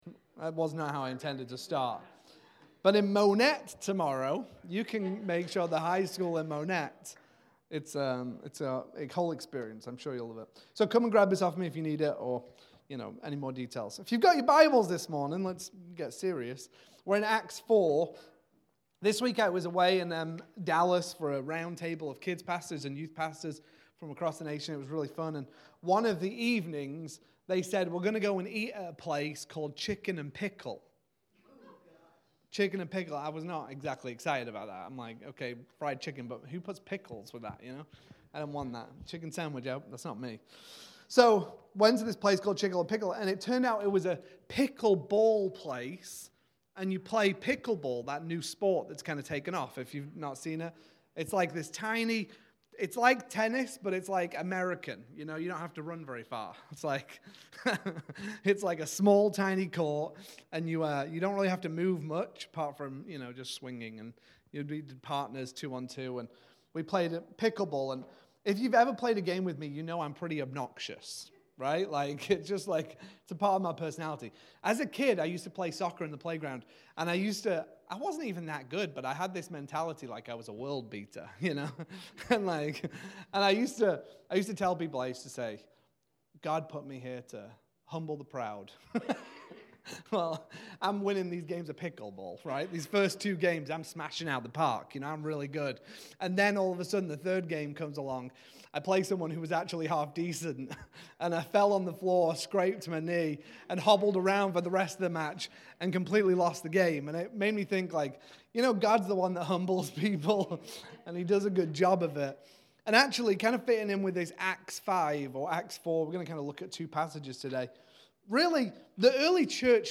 Sermons | Awaken Church